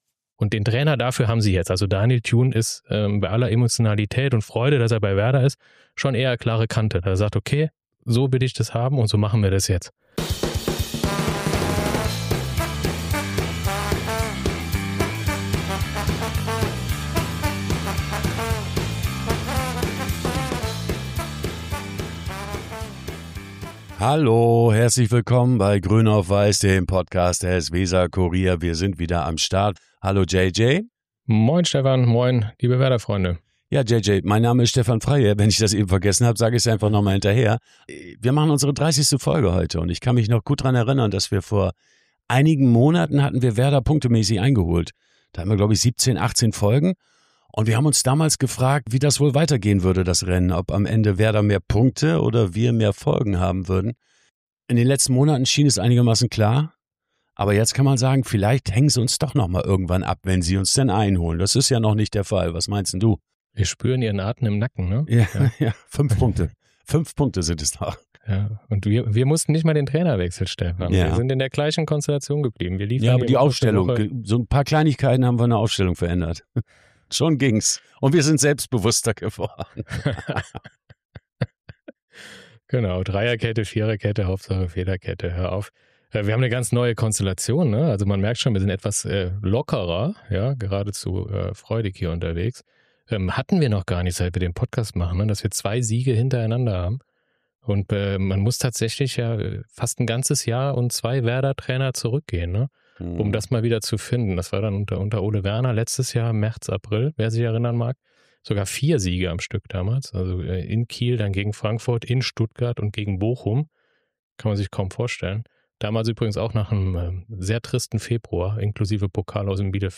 Was zwei Siege doch ausmachen: Lockere Stimmung bei Werder und auch eine gelöstere Atmosphäre in unserem Podcast.